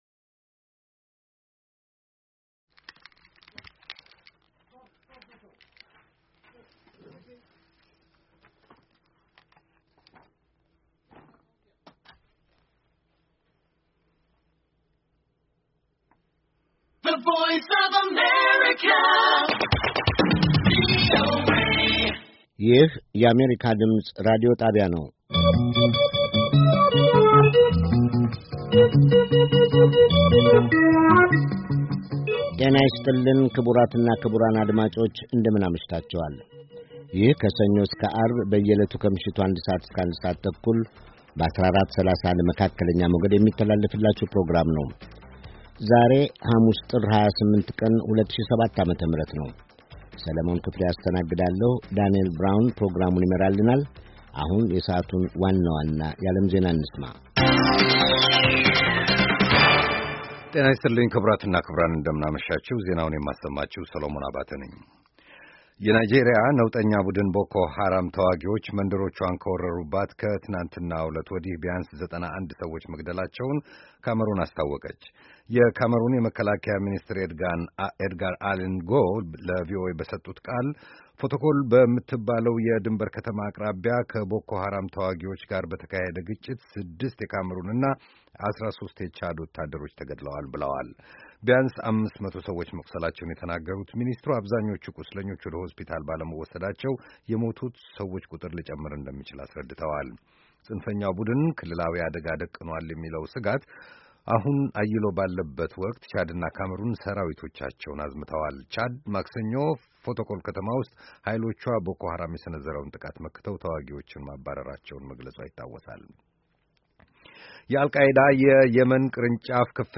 Early edition of Amharic News